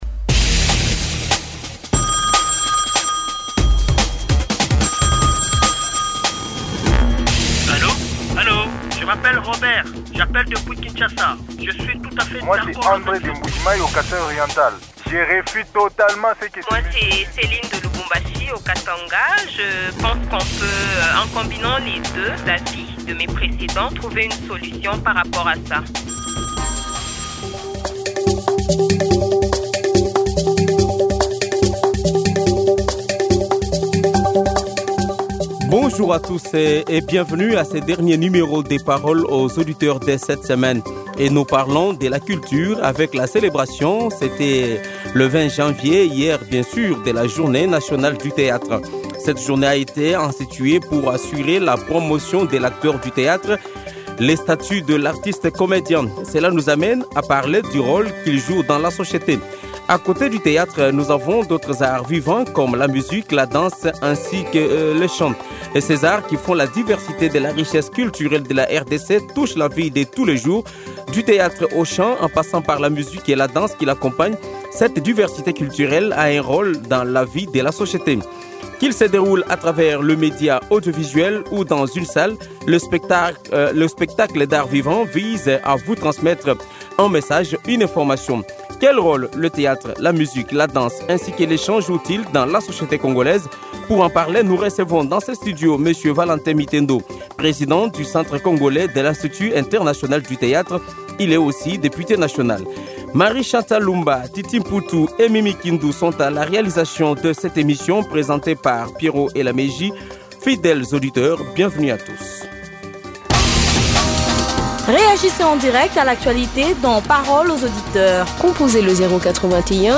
Quel rôle joue le théâtre dans votre milieu ? Invité: Valentin Mitendo, député national et artiste.